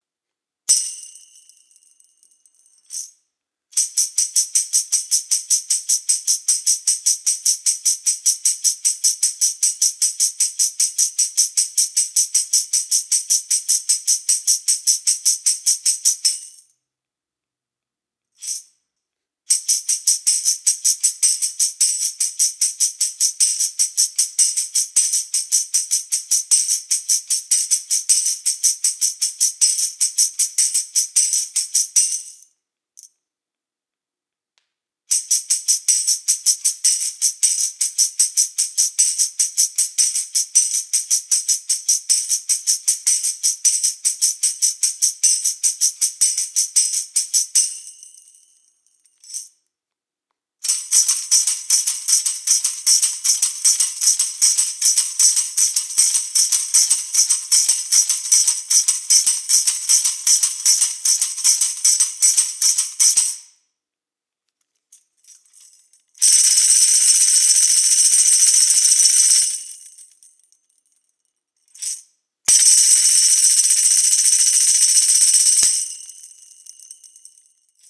MEINL Percussion Headliner® Series Molded ABS Tambourine - 8" (HTT8WH)
The MEINL Headliner® Series Tour Tambourines feature an extra wide grip and durable ABS plastic frames for use on the road.